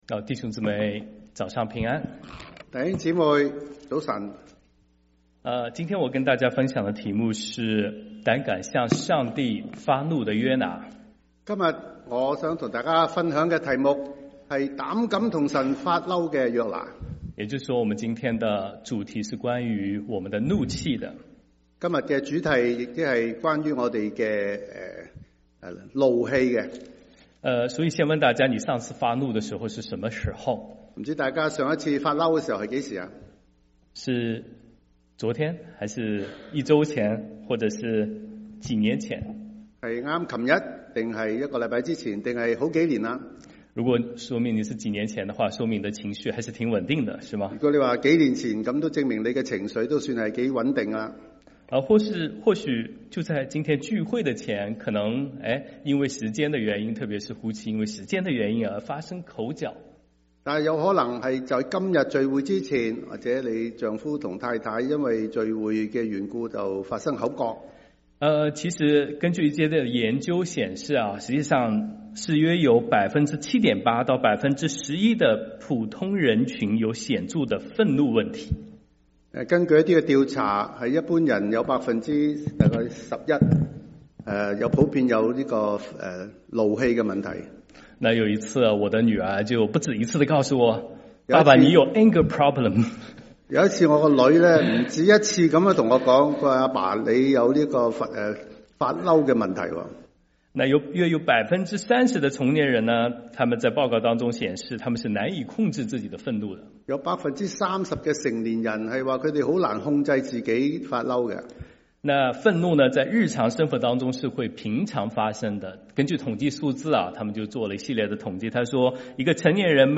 3/16/2025 國粵語聯合主日崇拜: 「敢跟神生氣的約拿」